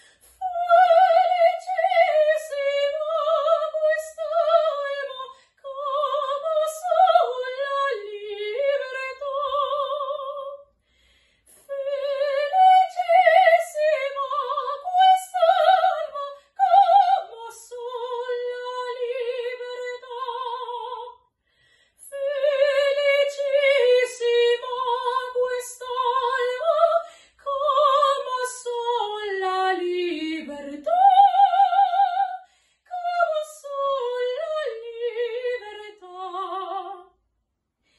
Además, durante estas dos sesiones online nos hemos podido deleitar con la espectacular voz de la soprano